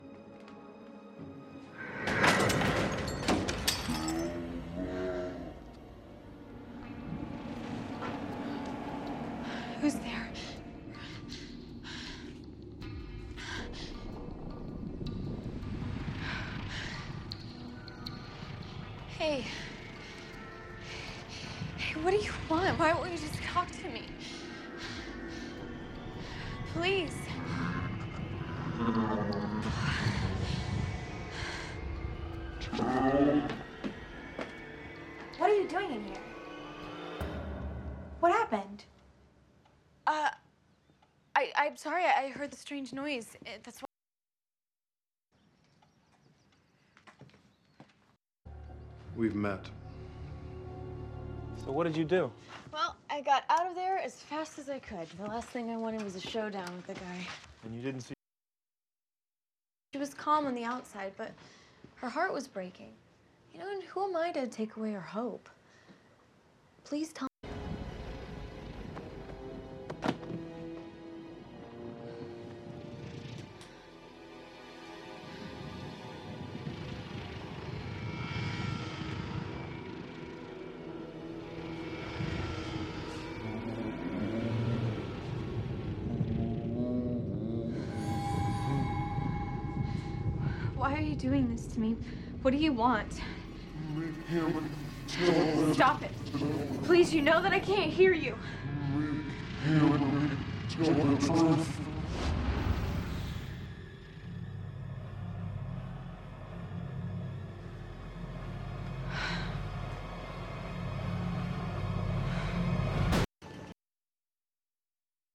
slowtalk.wav